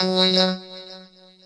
Vocoder " King Vocof 193
描述：Mashine ＆gt; KorgKingkorg＆gt; internalVocoder +标准舞台 麦克风，Digidesign Mbox上的录音机 原创。
标签： 机器人 KORG 声码器 电语音
声道立体声